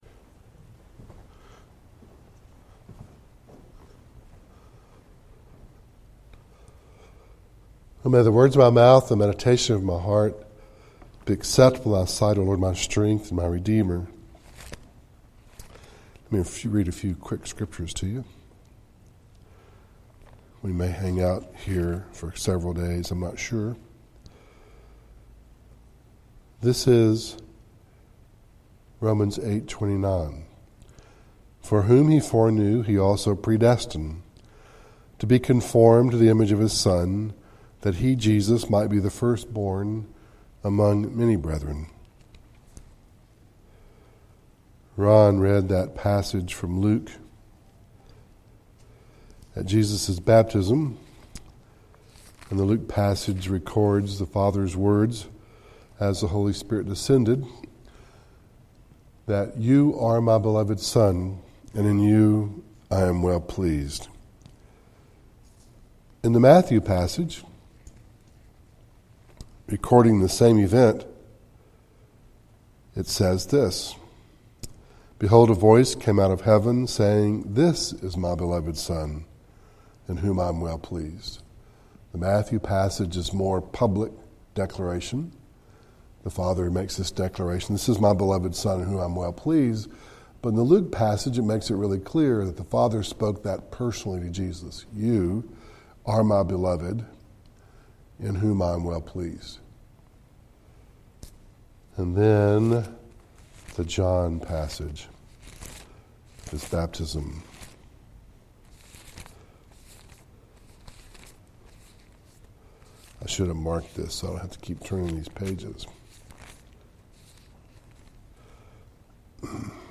Luke 2:1-6 Service Type: Devotional